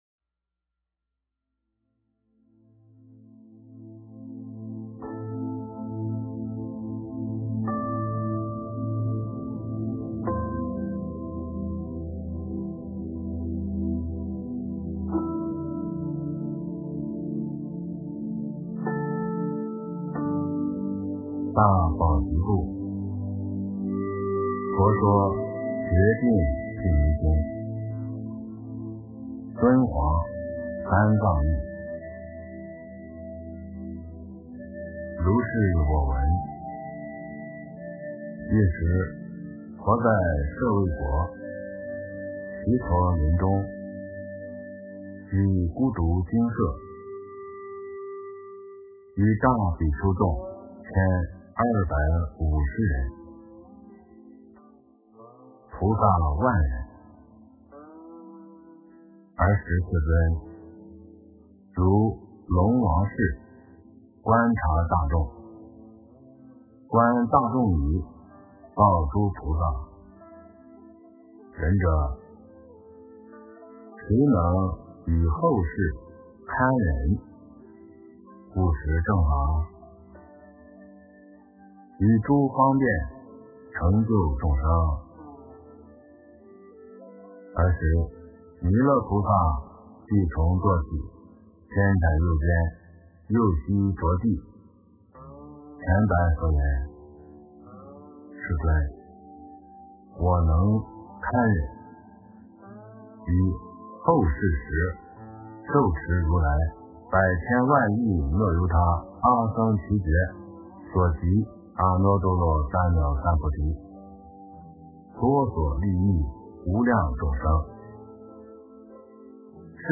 佛说决定毗尼经 - 诵经 - 云佛论坛